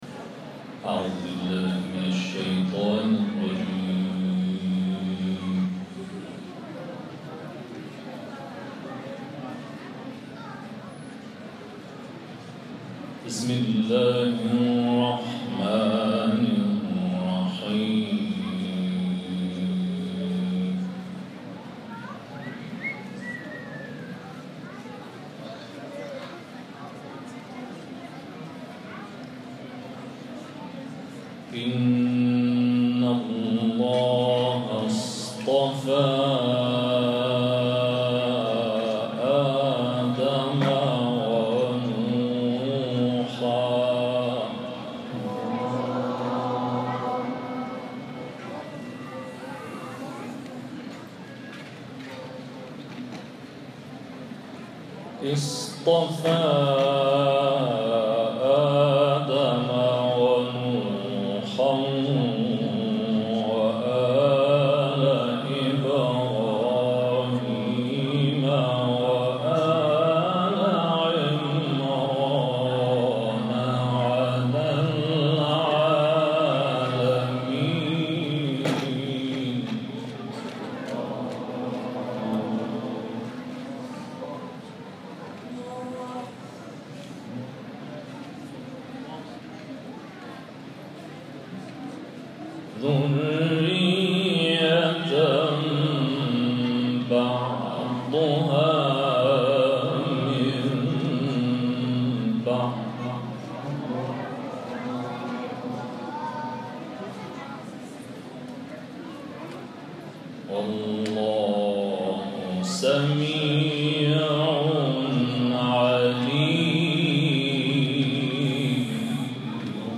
این تلاوت پنجشنبه 12 مرداد ماه شب ولادت حضرت علی بن موسی الرضا(ع) در برنامه کرسی تلاوت رضوی در شهر تبریز اجرا شده است.